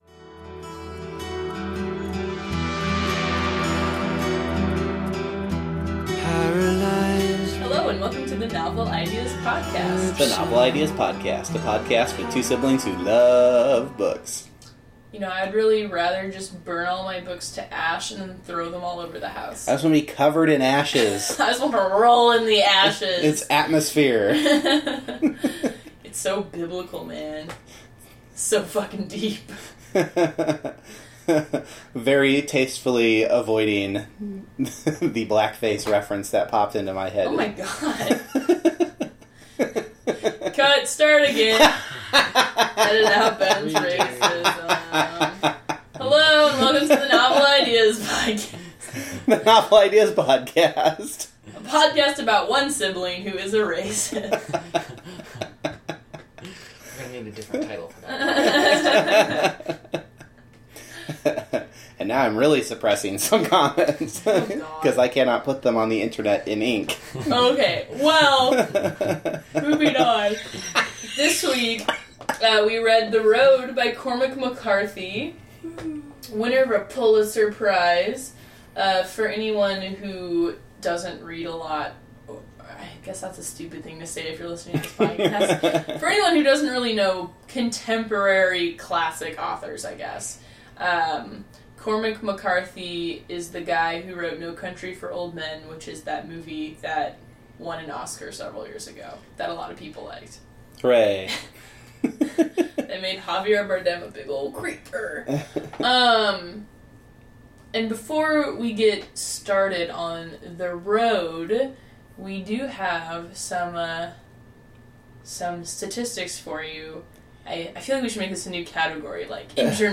Additionally, there’s a little bit of Patton Oswalt at the end of the podcast.